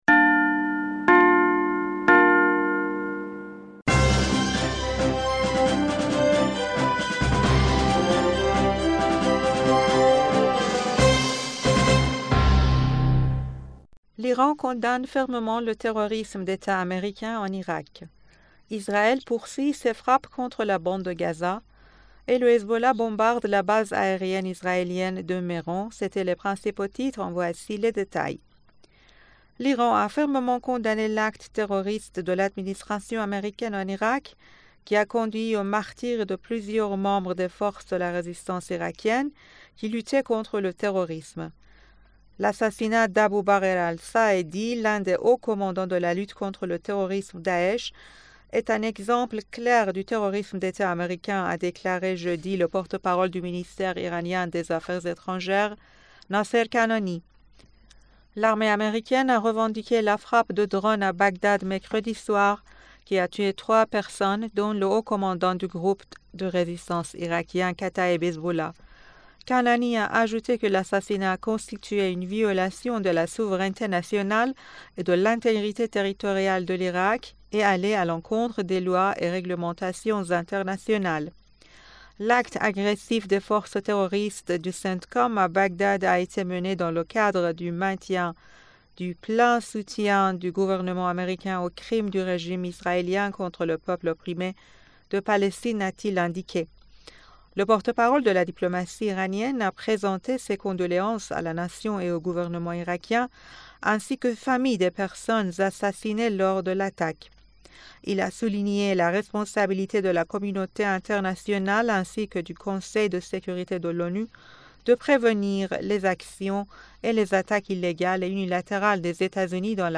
Bulletin d'information du 09 Fevrier 2024